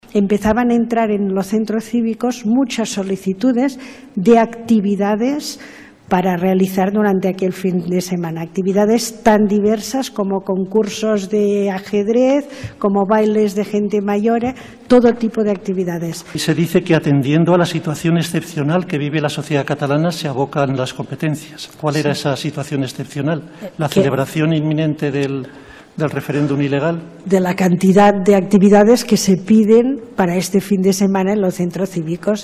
L’exconsellera torroellenca Dolors Bassa ha afirmat aquest dimecres a la tarda a la seva declaració davant del Tribunal Suprem al judici contra el procés ha afirmat que el referèndum “no va ser un acte concloent per a res, i menys per a la independència”. Ho ha dit en resposta al fiscal Fidel Cadena, que l’acusa de rebel·lió i malversació.